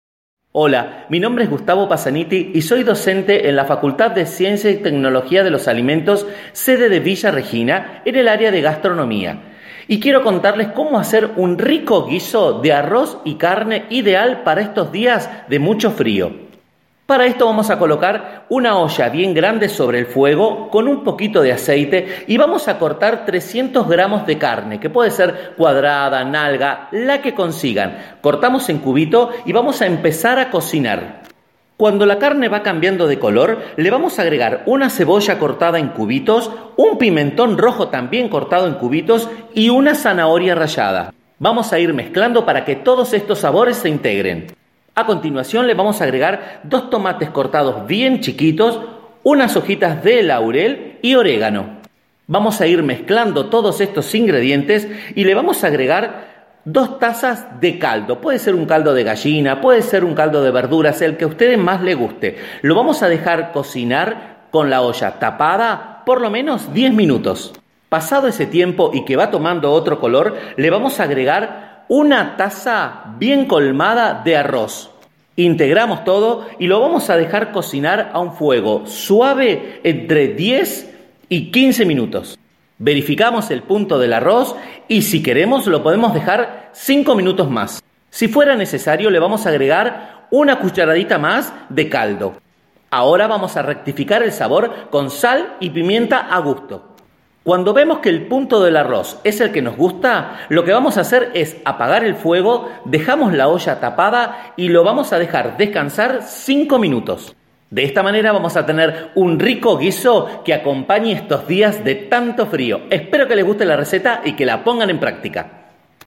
Audio receta del guiso de arroz y carne